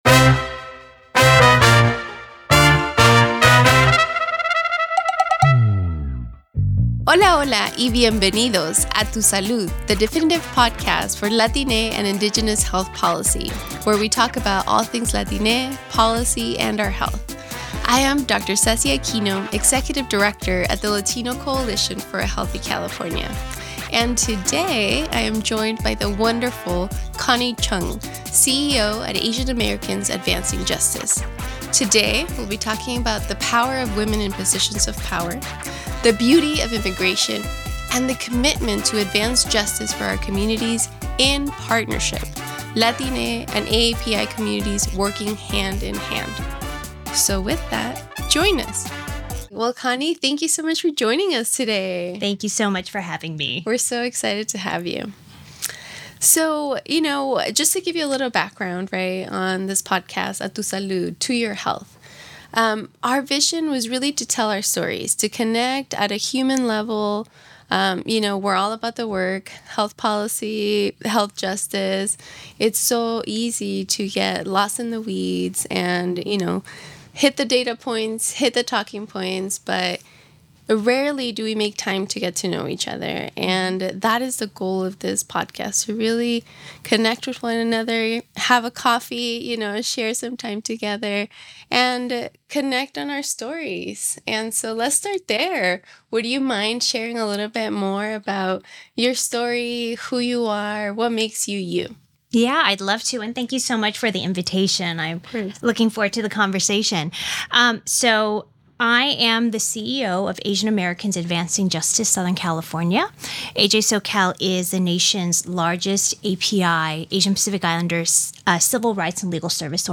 Some mild explicit language is used in this episode.